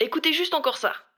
VO_ALL_Interjection_06.ogg